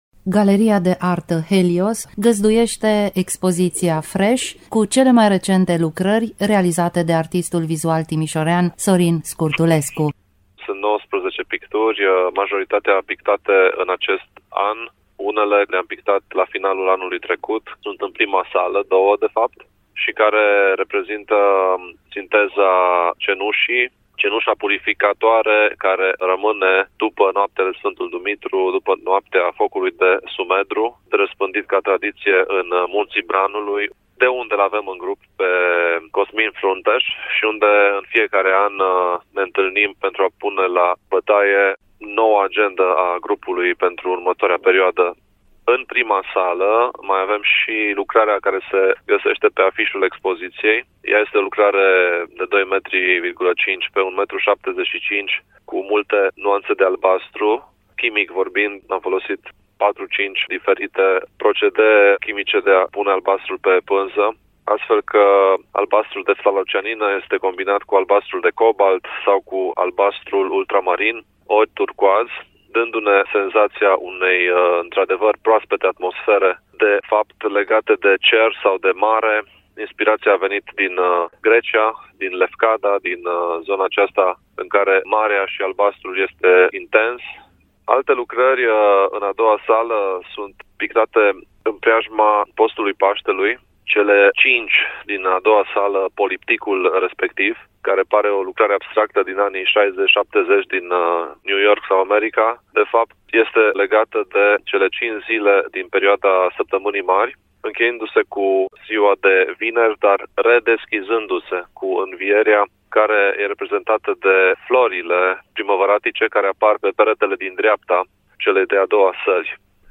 Detalii despre personala „Fresh” în dialogul